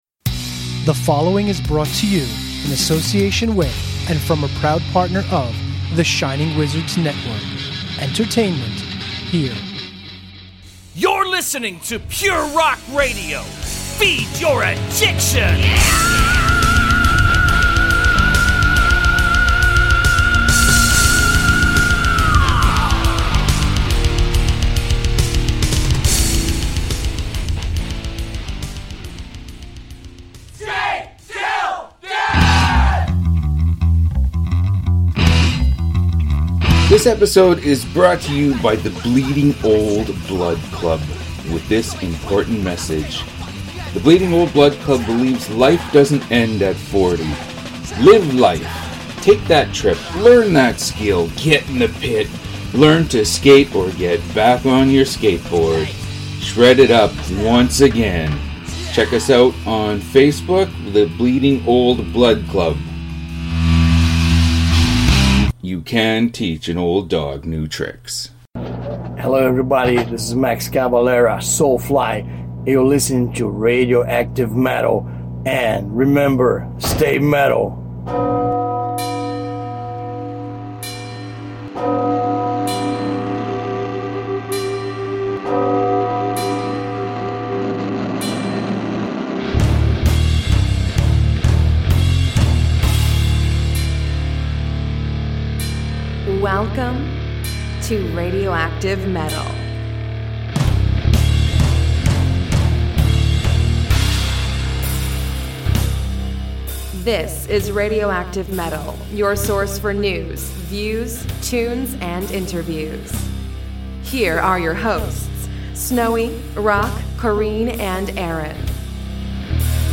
Radioactive Metal 595: Maximum RnR – interview with Max Cavalera